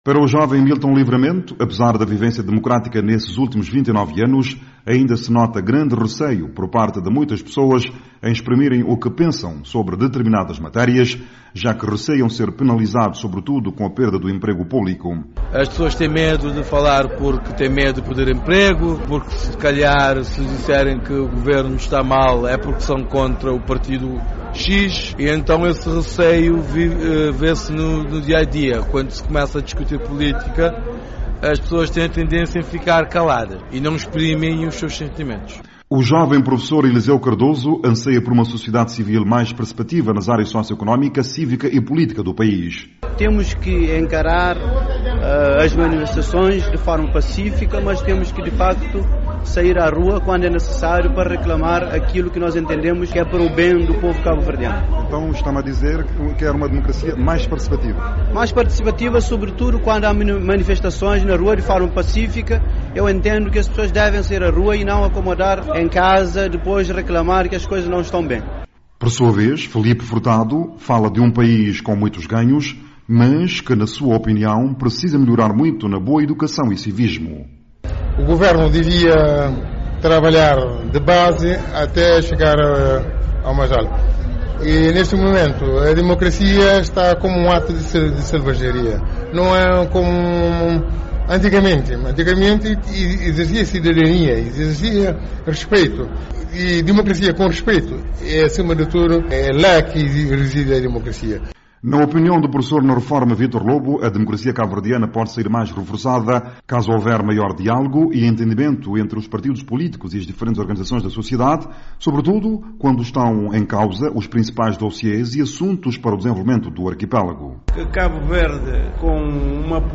Cidade da Praia, Cabo Verde
Para os cabo-verdianos, a instauração do regime democrático trouxe ganhos assinaláveis para o país, mas algumas pessoas ouvidas pela VOA, na cidade da Praia, consideram que são precisos ainda mais mecanismos que permitam acabar com a auto-censura nas diferentes esferas, bem como a necessidade de haver maior civismo e participação activa no desenvolvimento.